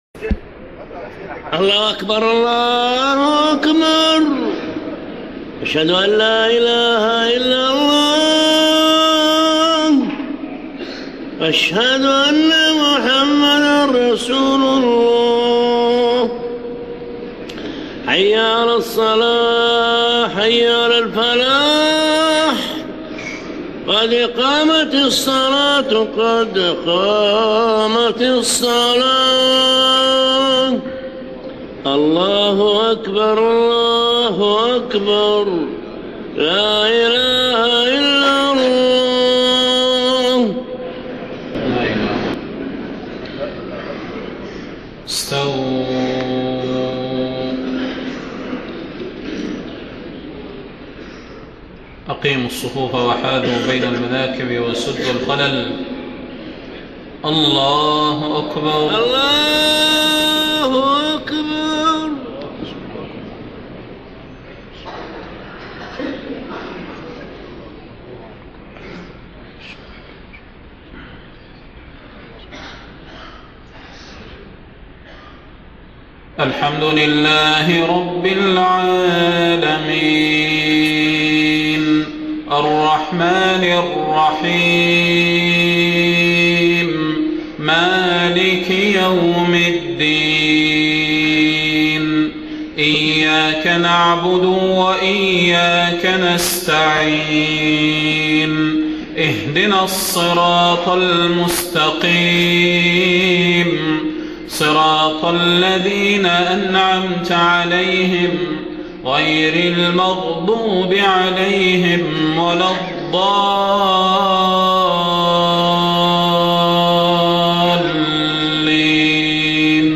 صلاة المغرب 9 محرم 1430هـ سورتي الكافرون و الإخلاص > 1430 🕌 > الفروض - تلاوات الحرمين